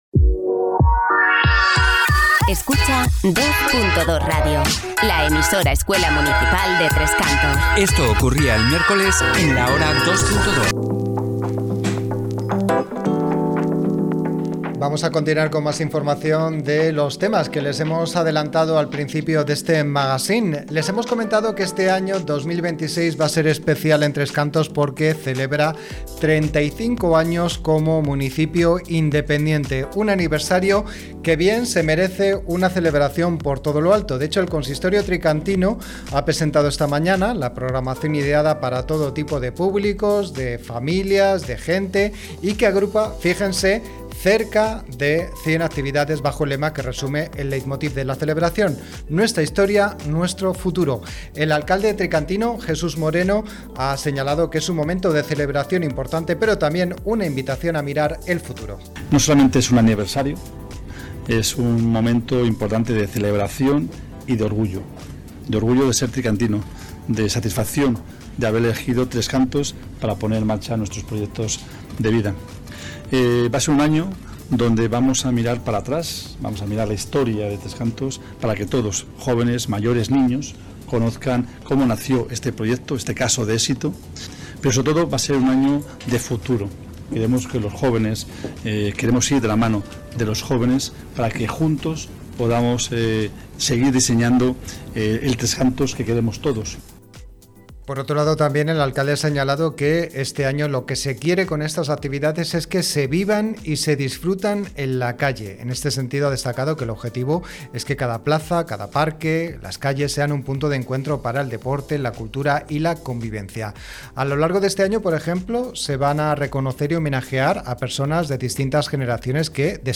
REPORTAJE-ANIVERSRIO-TC.mp3